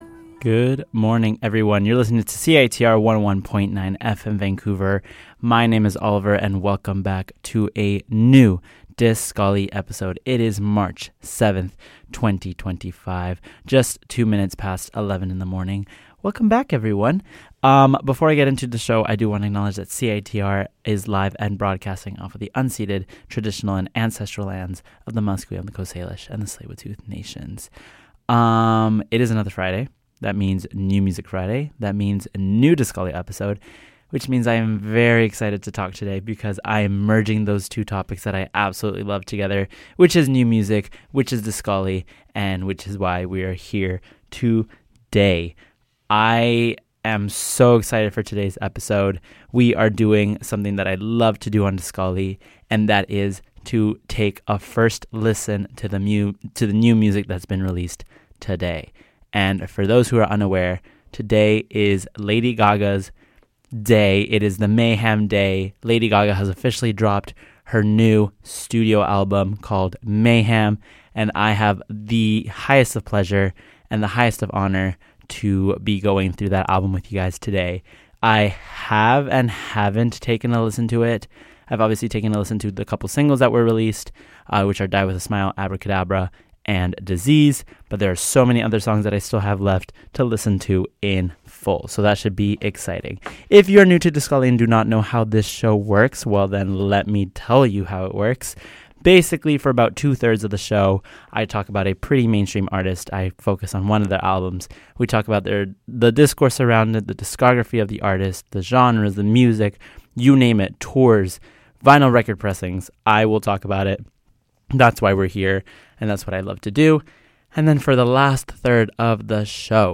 dark-pop, electronic, synth-pop, and dance music